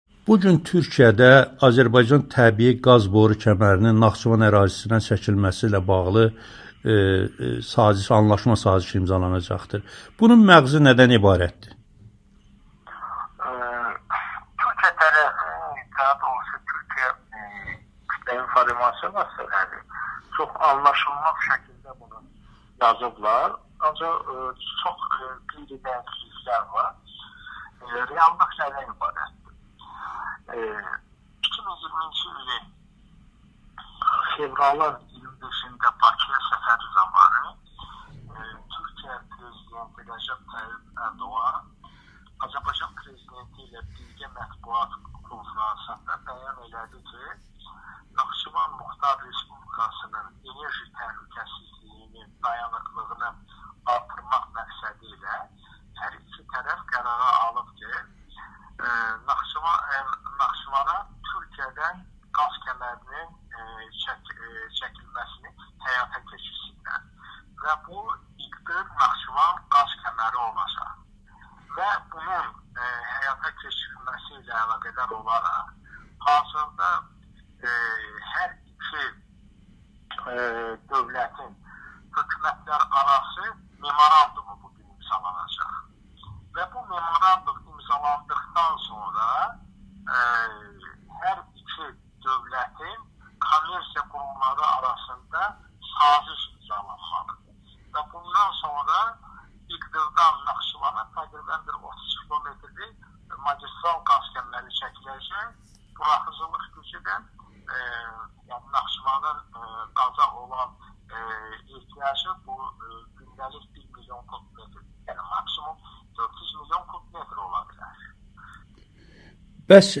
Amerikanın Səsinə müsahibəsində bildirib ki, memorandum imzalandıqdan sonra kəmərin çəklişi ilə bağlı kommersiya sazişi imzalanacaq.